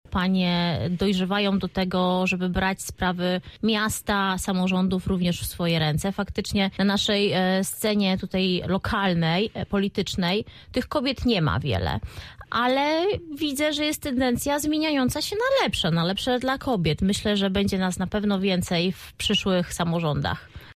Jak podkreślił nasz dzisiejszy gość, w różnych sferach życia jest co raz lepiej.